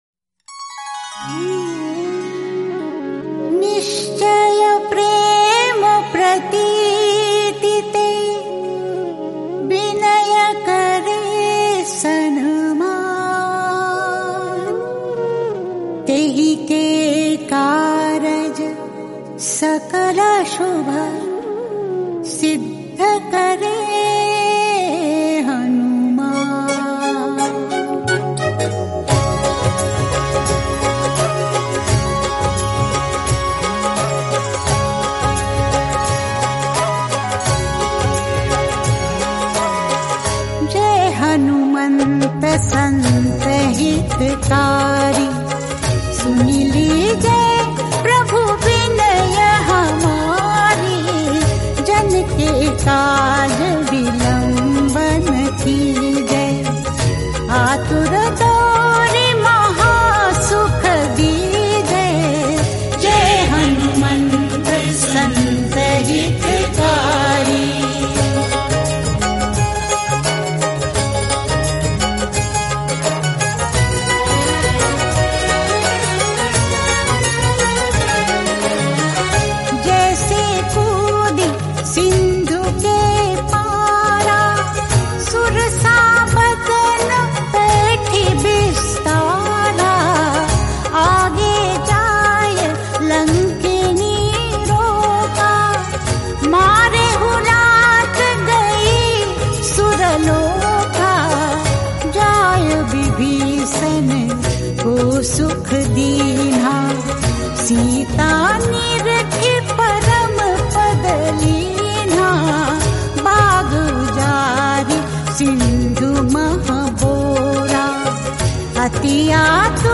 melodious and devotional voice